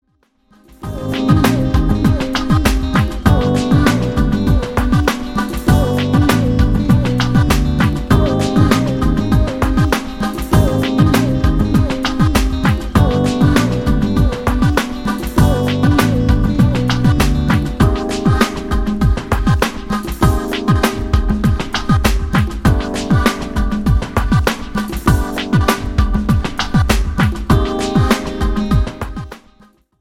JAZZ  (02.30)